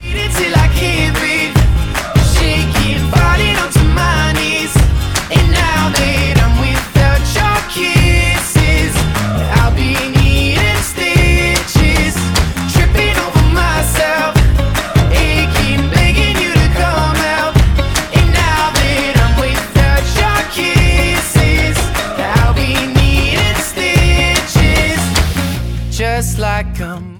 • Pop Ringtones
a pop ballad